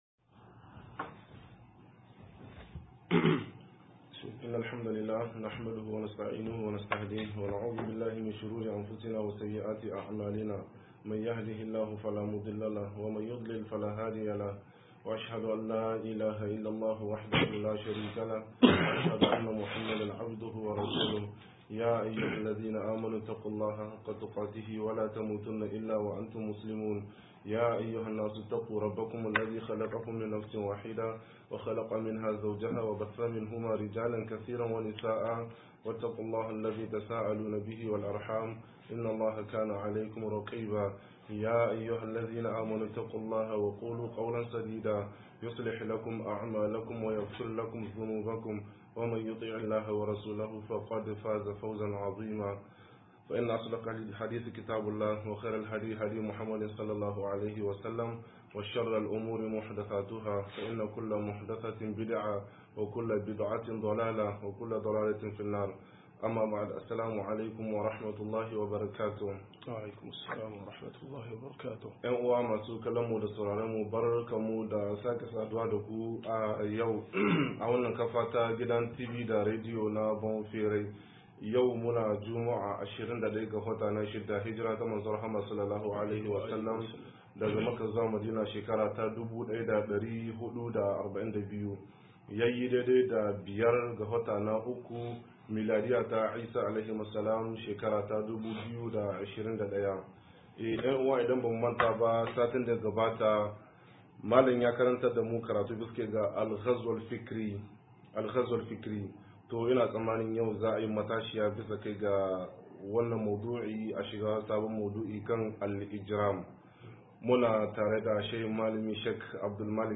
118-Yakar Hankula 3 - MUHADARA